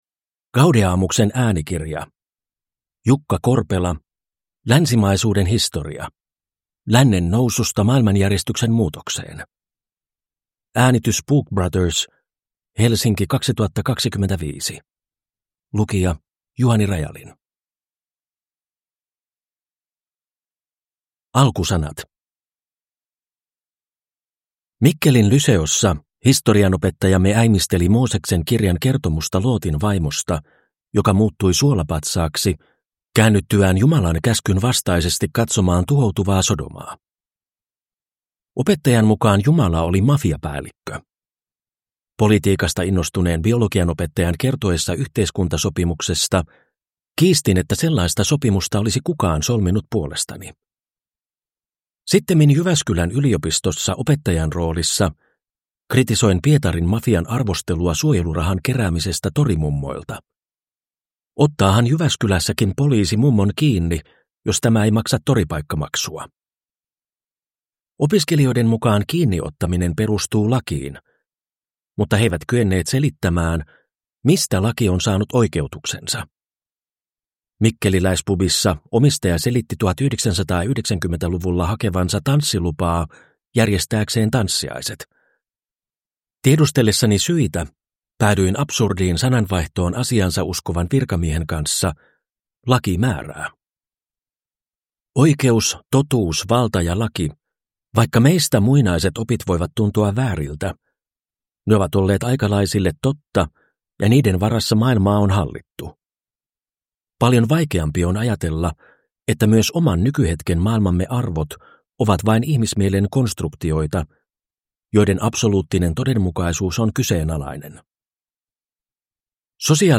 Länsimaisuuden historia – Ljudbok